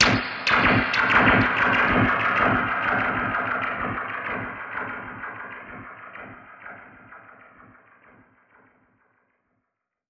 Index of /musicradar/dub-percussion-samples/95bpm
DPFX_PercHit_D_95-05.wav